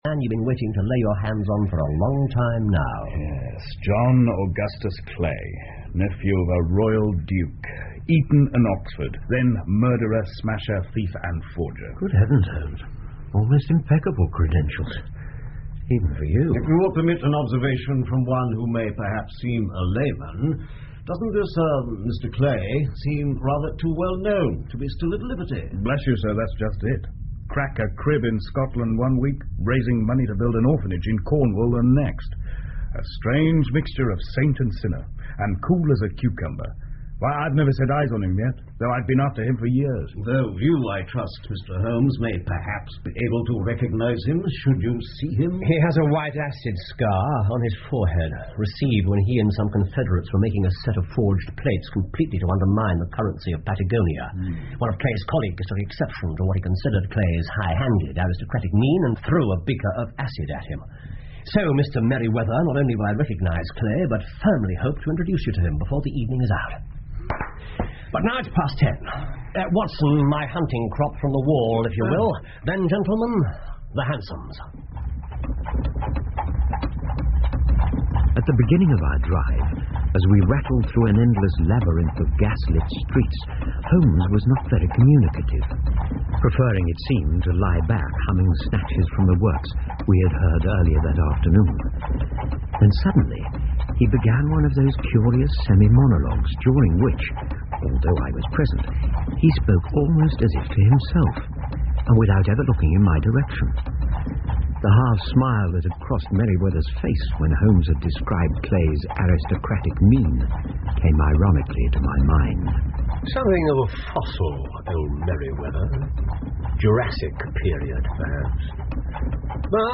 福尔摩斯广播剧 The Red Headed League 8 听力文件下载—在线英语听力室